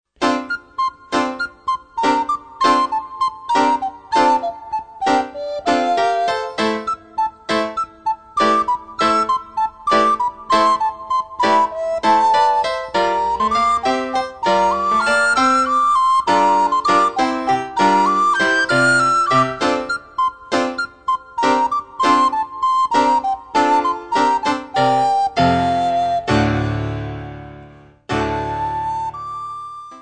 für Sopranblockflöte und Klavier
Besetzung: Sopranblockflöte und Klavier
Klassisches Blockflötenrepertoire auf Noten mit Playback-CD.